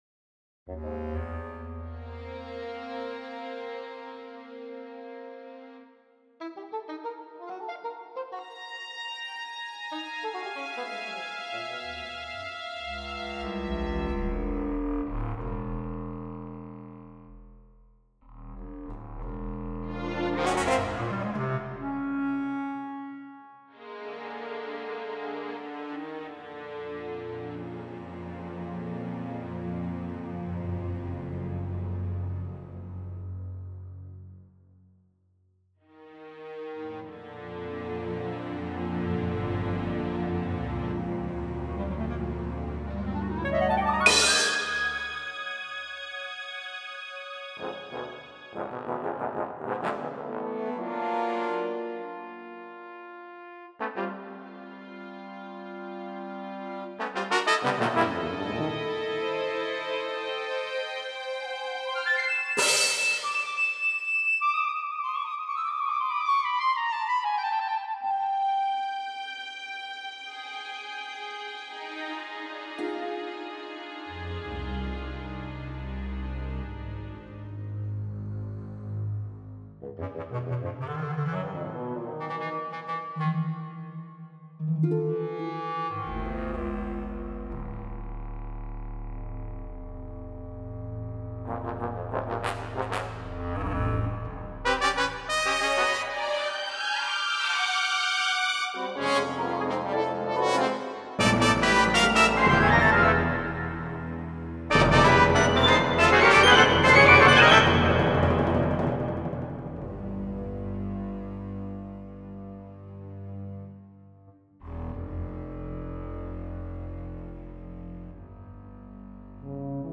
A modern piece. Chromatic. Mostly calm, drowsy.